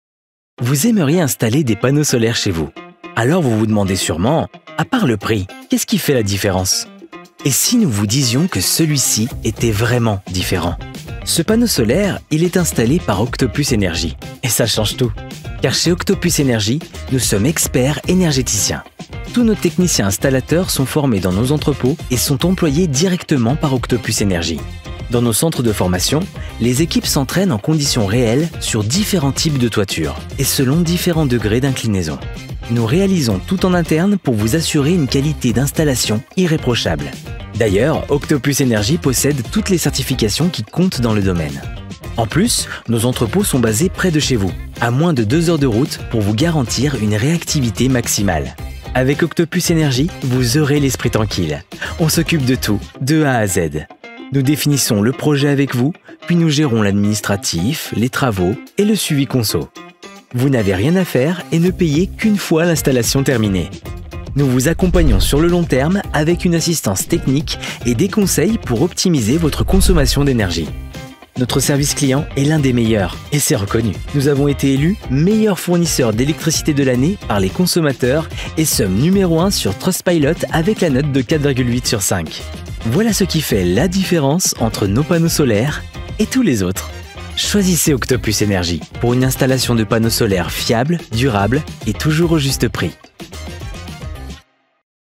Young, Natural, Playful, Friendly
Corporate